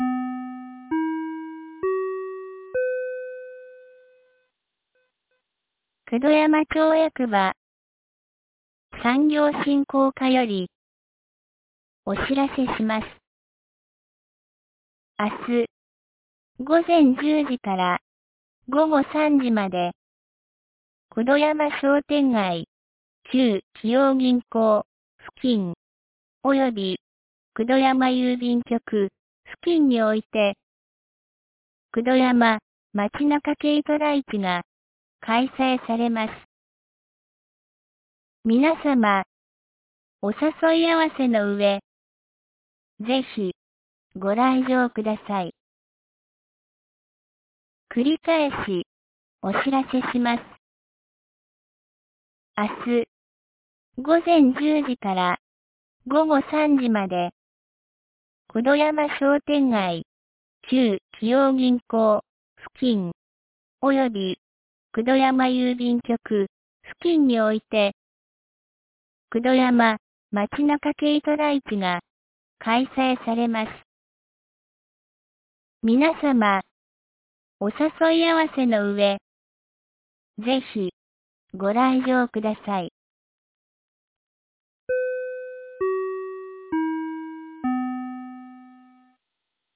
2023年09月16日 16時06分に、九度山町より全地区へ放送がありました。